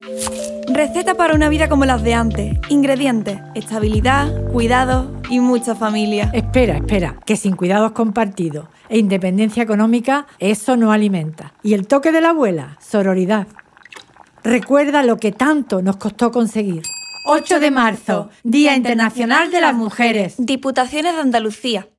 Cuña radio